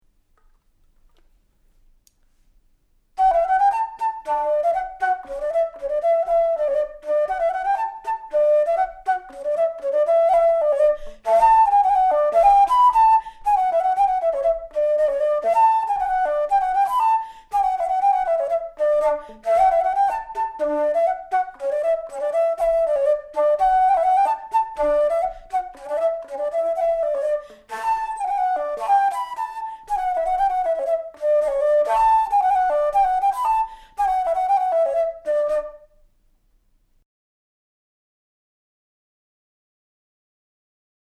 Traditsiooniliste eesti viiulilugude interpreteerimine flöödil 1937. aastal helisalvestatud Jaan Palu lugude näitel
loomingulise magitrieksami kirjalik osa, viiul, flööt
2. Pillimuusika